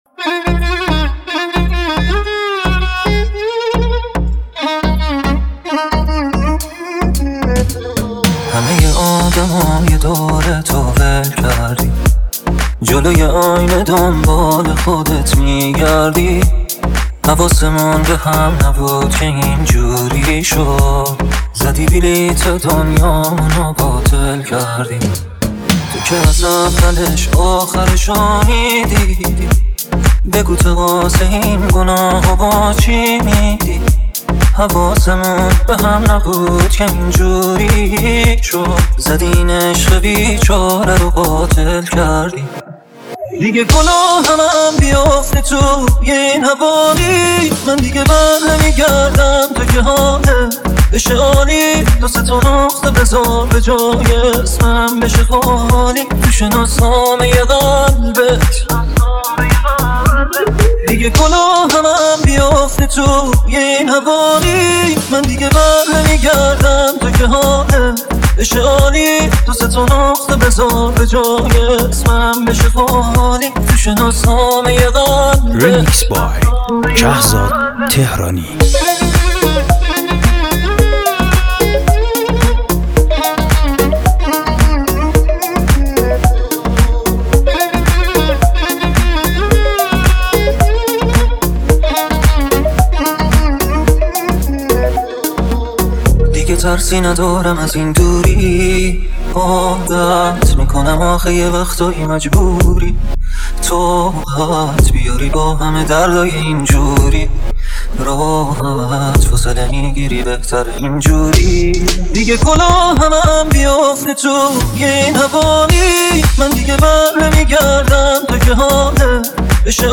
تند بیس دار
ریمیکس